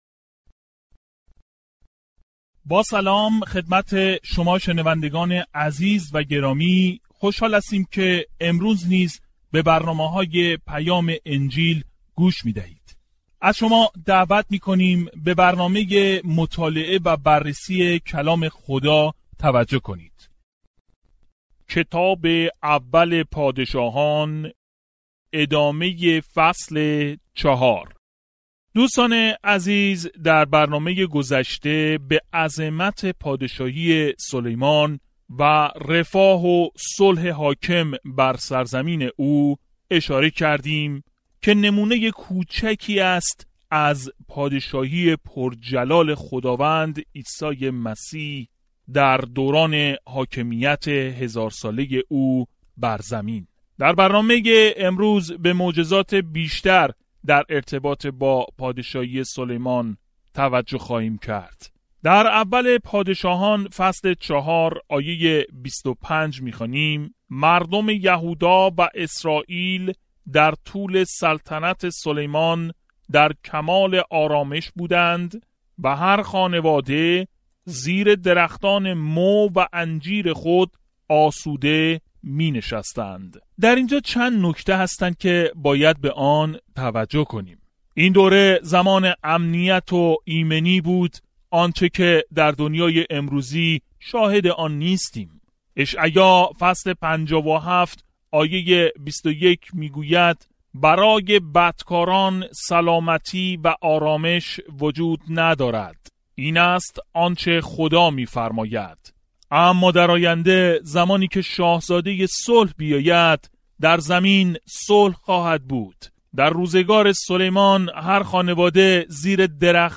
There is an audio attachment for this devotional.